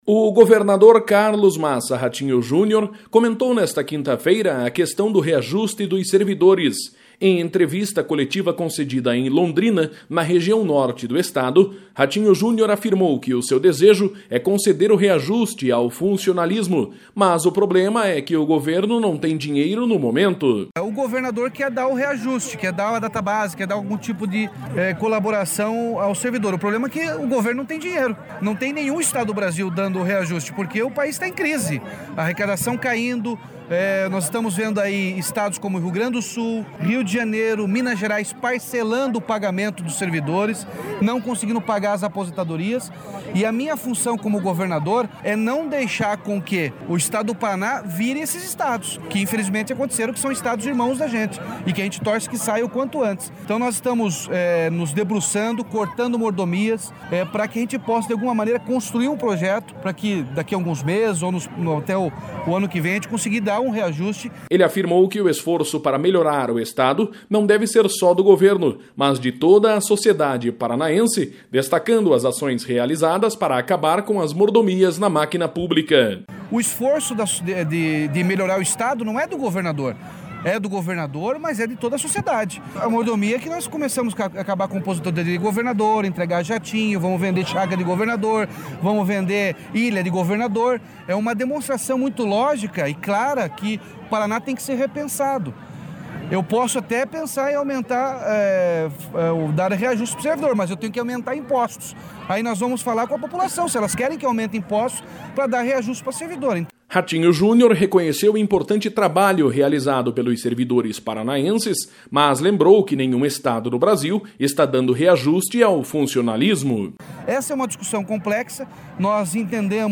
Governador Ratinho Junior fala sobre dificuldade para o reajuste dos servidores paranaenses
O governador Carlos Massa Ratinho Junior comentou nesta quinta-feira a questão do reajuste dos servidores. Em entrevista coletiva concedida em Londrina, na região norte do estado, Ratinho Junior afirmou que o seu desejo é conceder o reajuste ao funcionalismo, mas o problema é que o governo não tem dinheiro no momento.